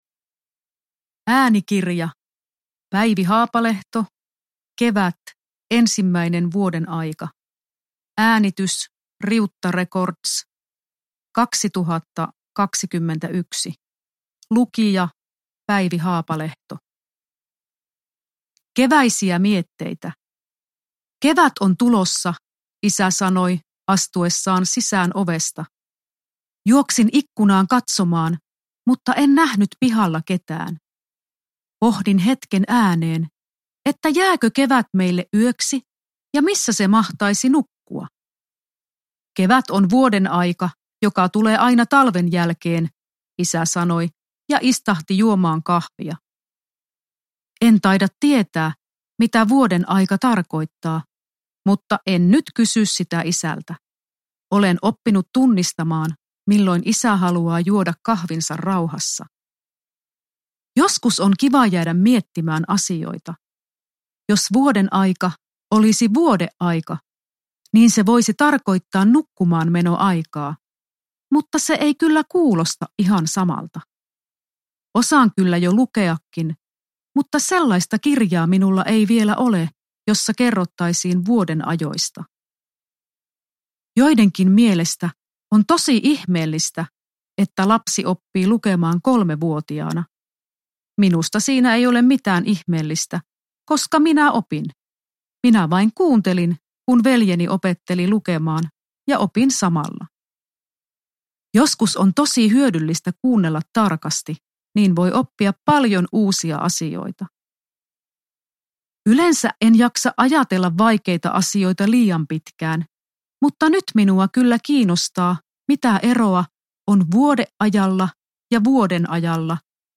Kevät – Ljudbok – Laddas ner